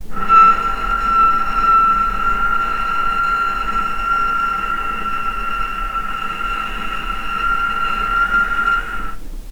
vc-E6-pp.AIF